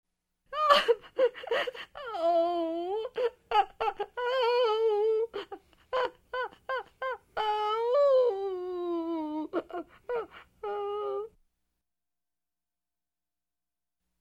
На этой странице собраны разнообразные звуки плача: от тихого всхлипывания до громких рыданий.
Женский плач со вздохами звук